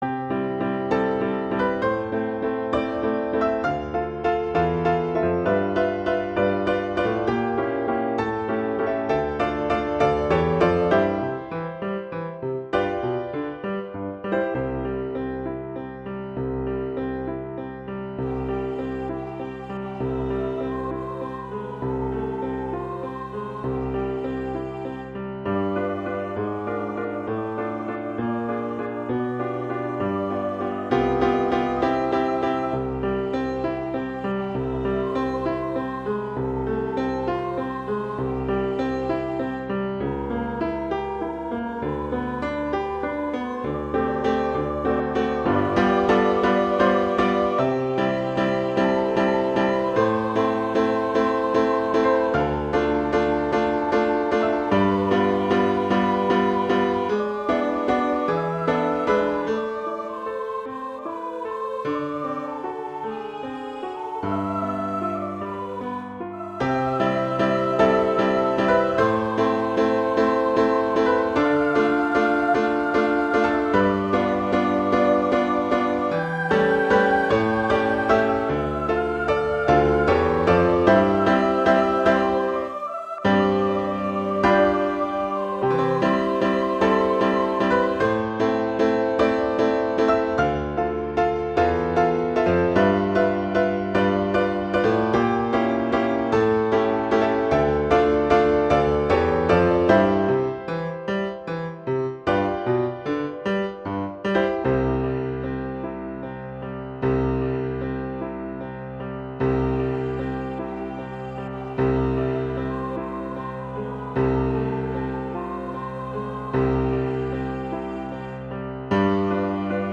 C major
♩=66 BPM